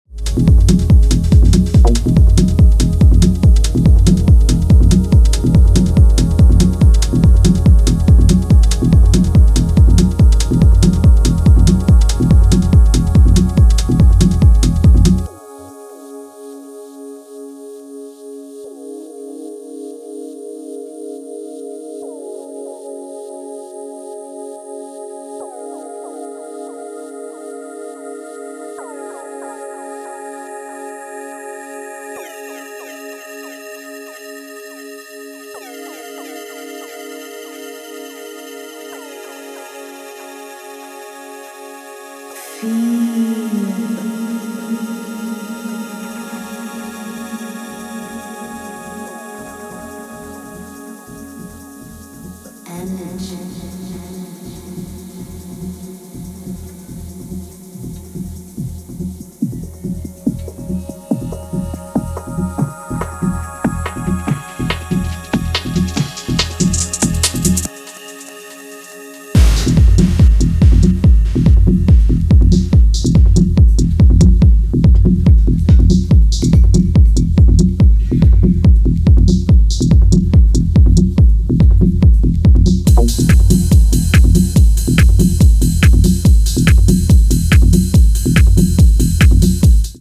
Techno